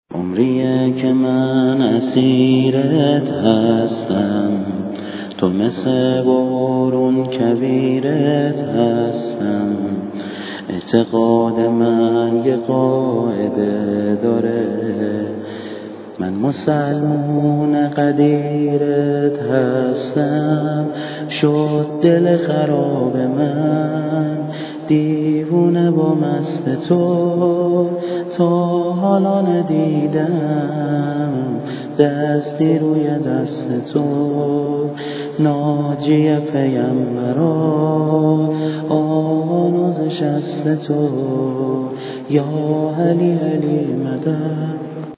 شور
چقدر اکو زیاد درنتیجه صداها گنگه